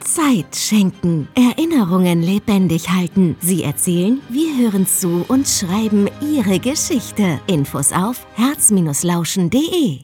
Funkspot-Herzlauschen.mp3